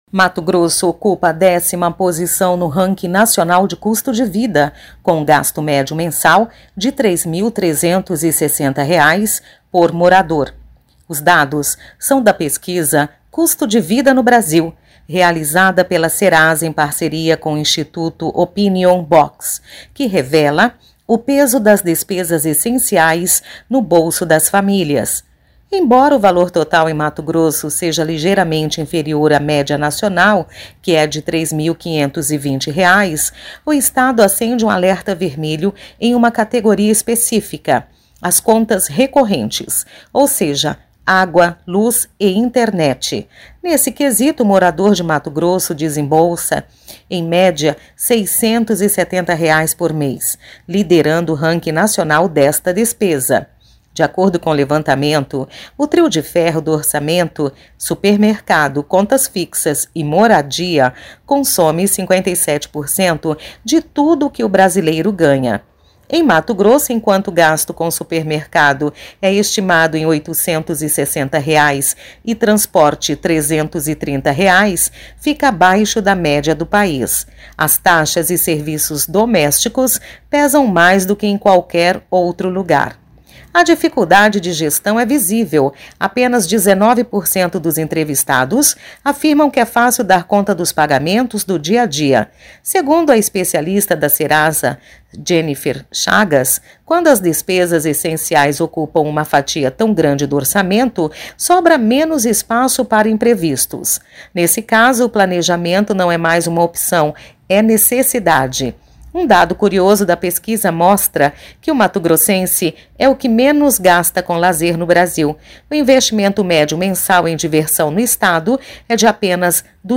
Boletins de MT 18 fev, 2026